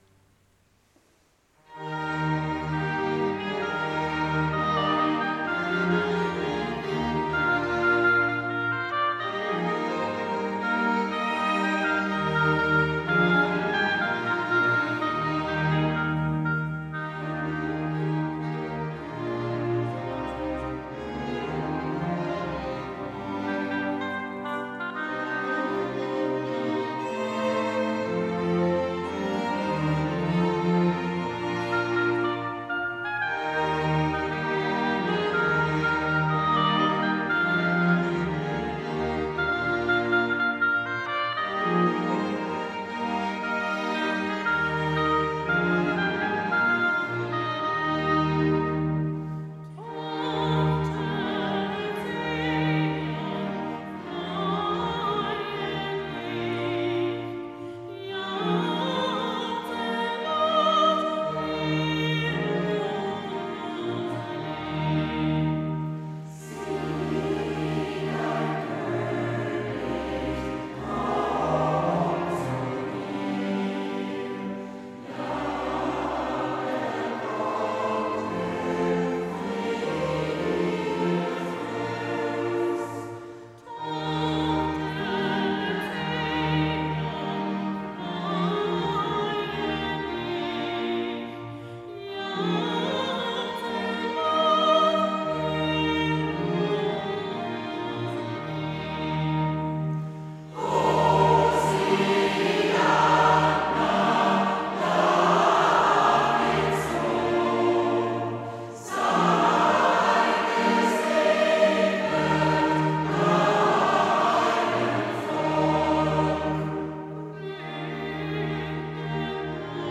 Auch die nachfolgenden Lieder (eingestellt als "Lied der Woche" im Advent 2021 und zu Weihnachten 2021) stammen aus diesem Konzert: